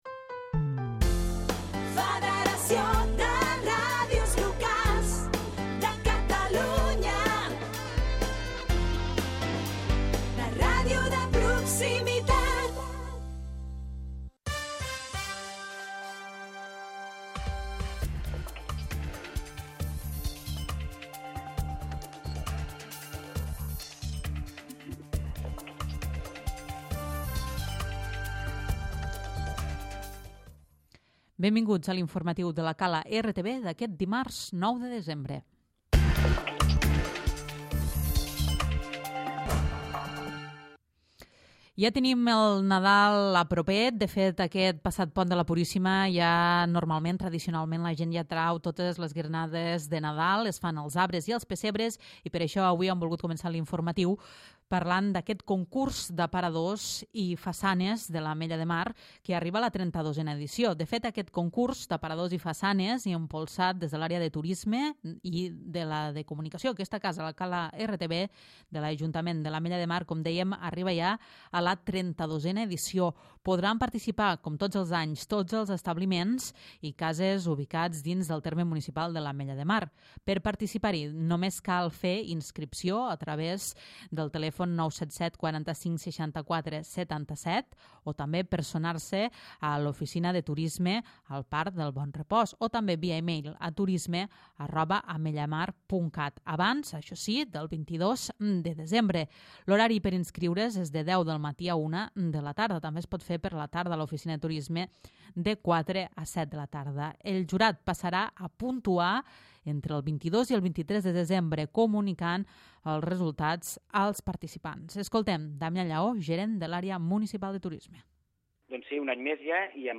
Butlletí Informatiu
Butlletí Informatiu d'avui dimarts, dia 9 de desembre